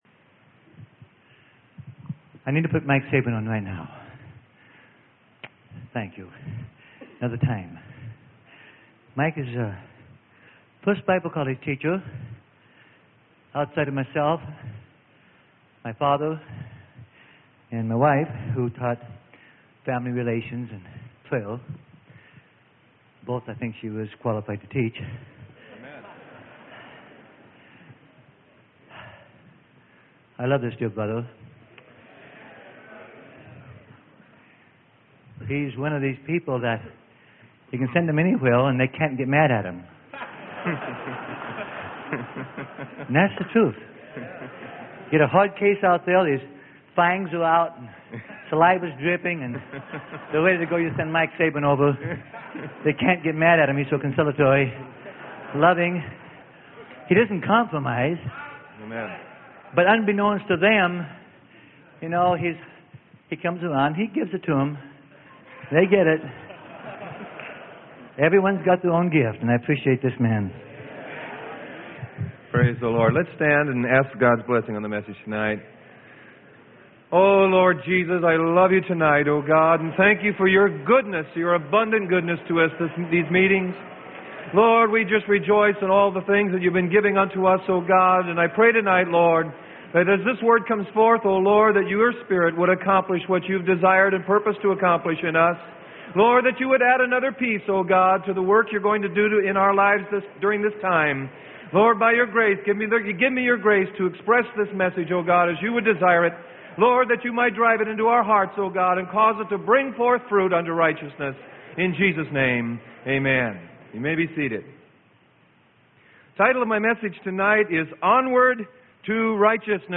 Sermon: ONWARD TO RIGHTEOUSNESS - Freely Given Online Library